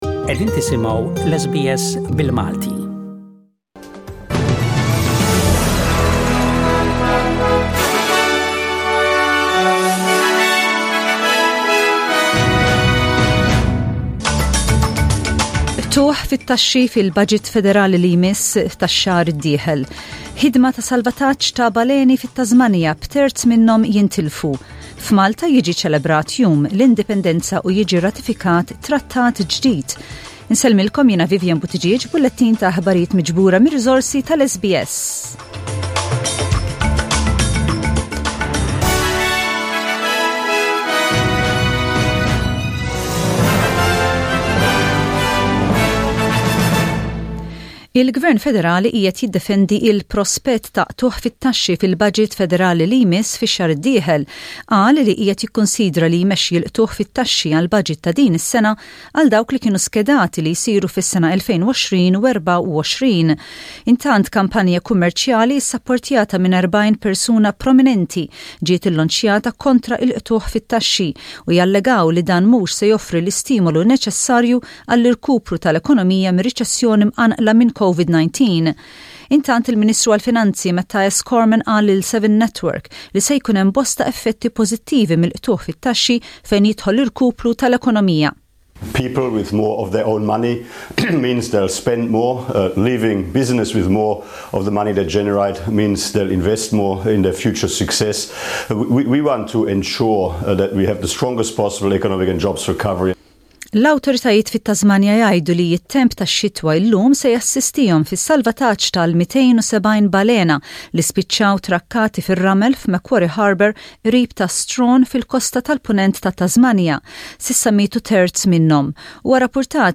SBS Radio | News in Maltese: 22/09/20